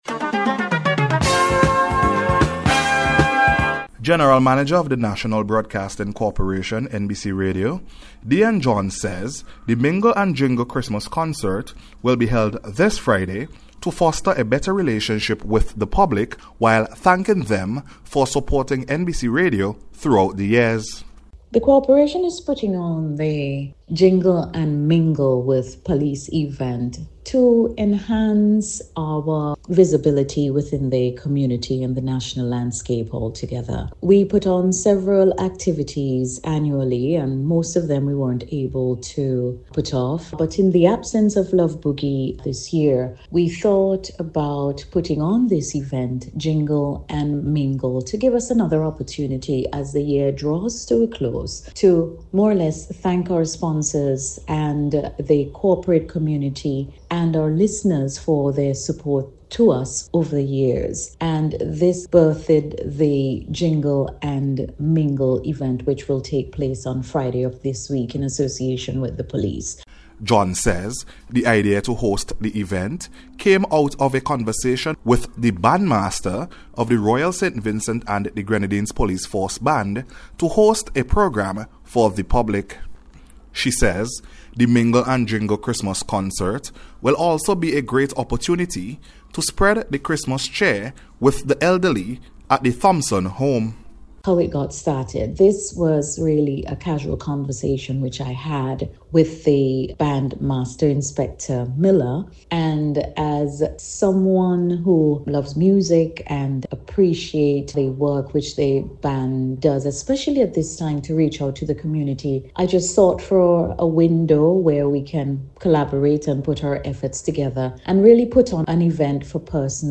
NBC’s Special Report- Monday 2nd December,2024